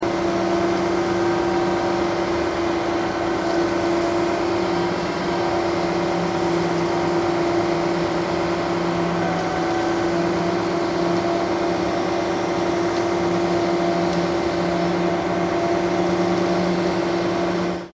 Качество записей проверено – никаких лишних шумов, только чистый звук техники.
Гул двигателя автобетономешалки